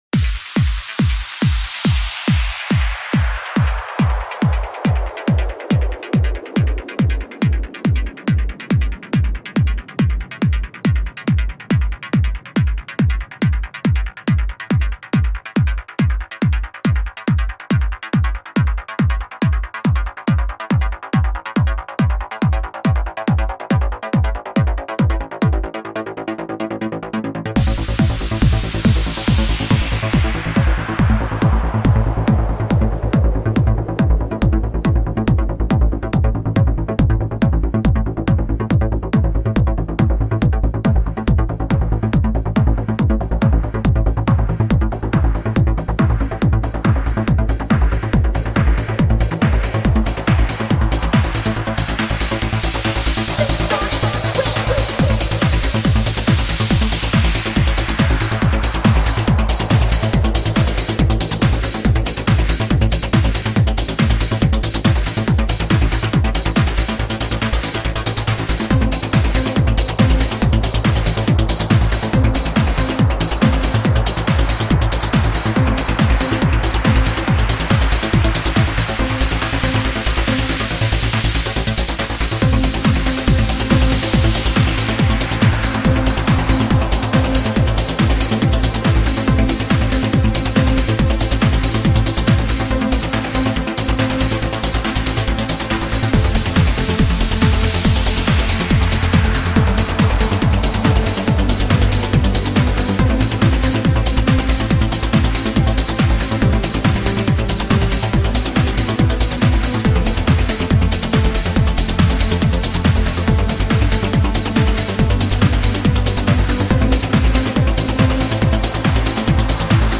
Full out uplifting trance this time!